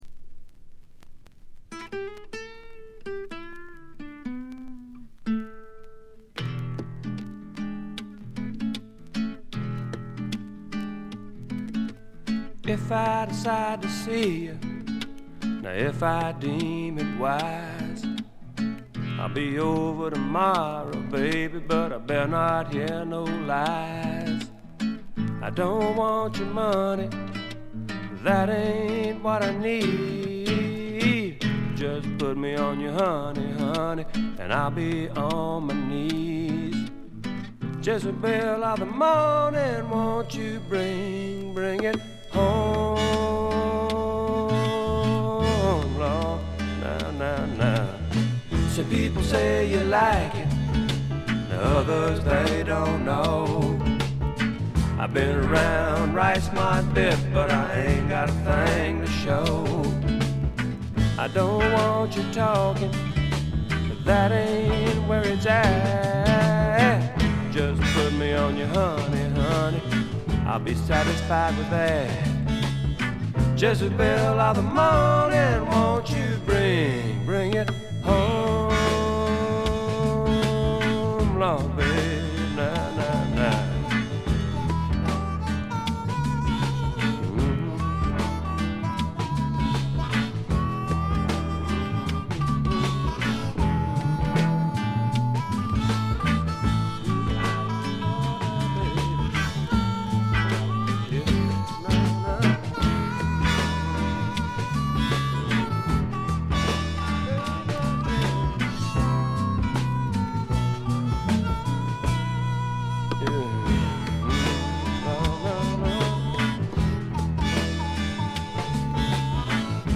部分試聴ですが、ごくわずかなノイズ感のみ。
あまりナッシュビルぽくないというかカントリーぽさがないのが特徴でしょうか。
試聴曲は現品からの取り込み音源です。
Guitar, Vocals, Piano, Vibes